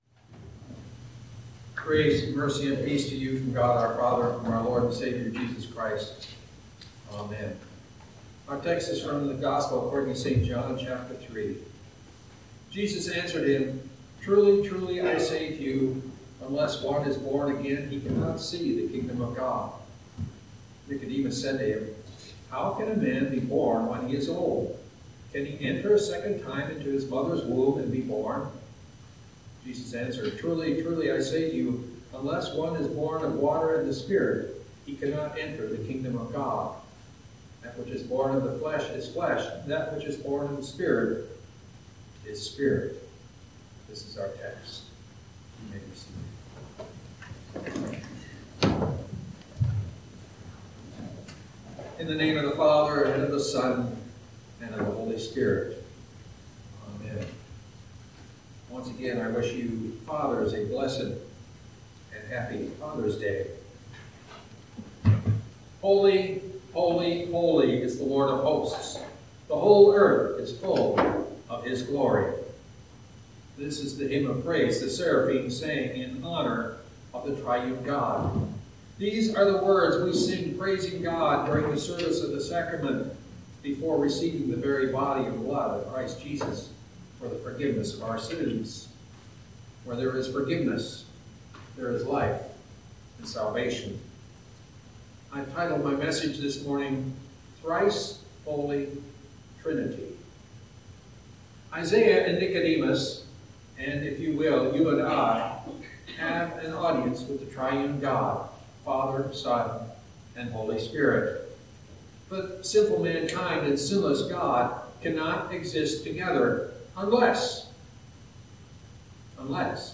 A sermon from the season "Trinity 2025." No matter how much the world changes, we can be confident because Jesus does not change.